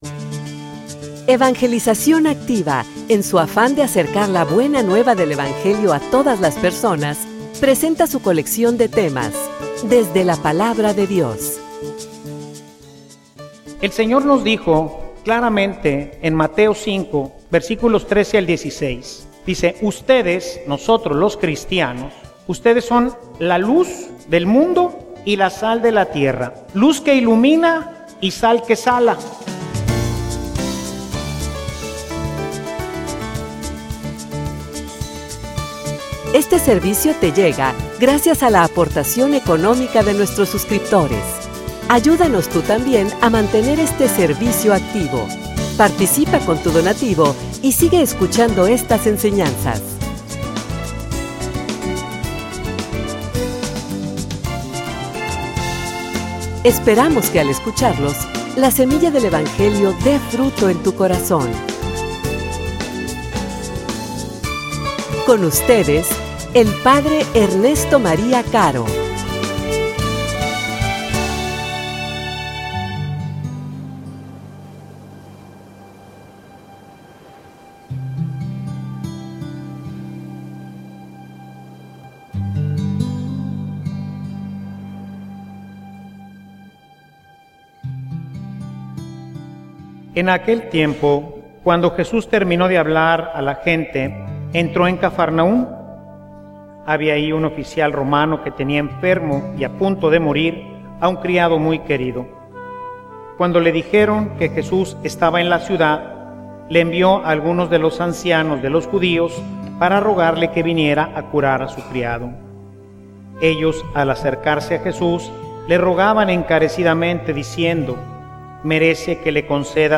homilia_El_que_quiera_seguirme.mp3